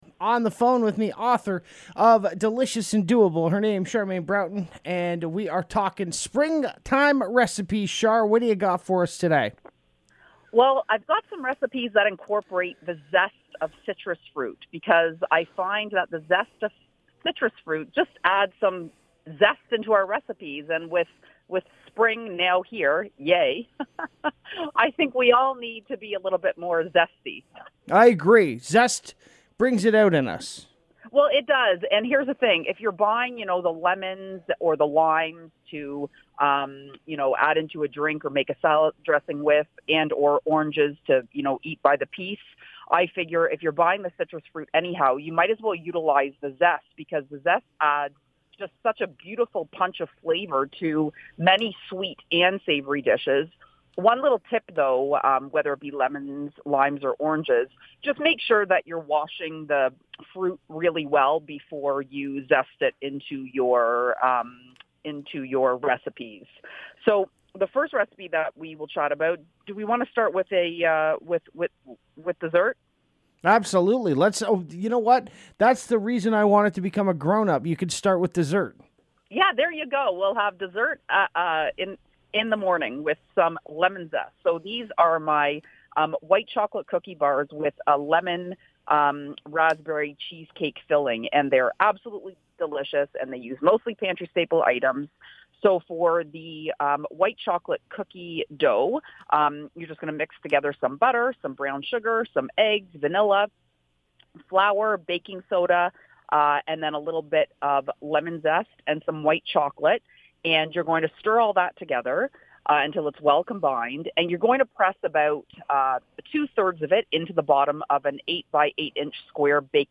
Here is the interview!